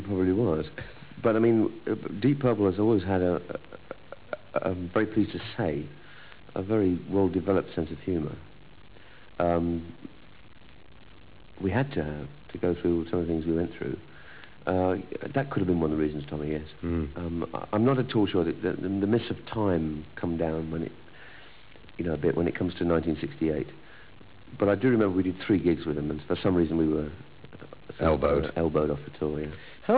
THE TOMMY VANCE INTERVIEWS